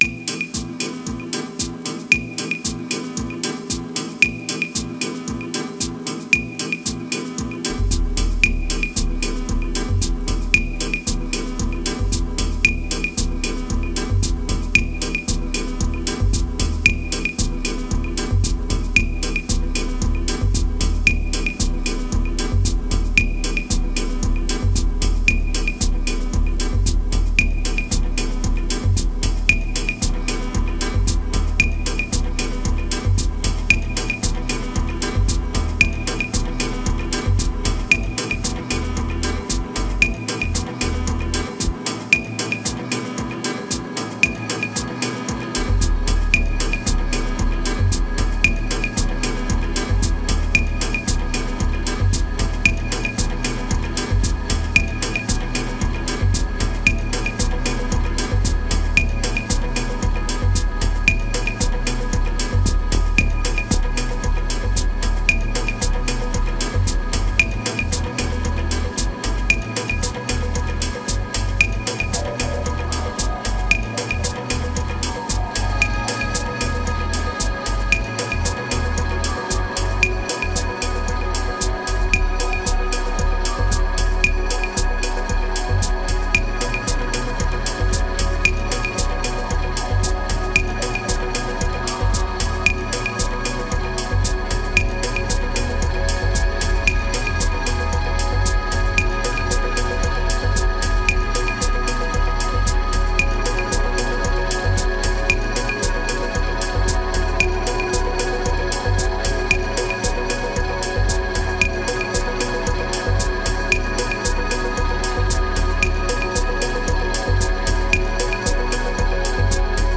1764📈 - 92%🤔 - 114BPM🔊 - 2023-09-10📅 - 622🌟
VIsualize: one sample, one chord, one take.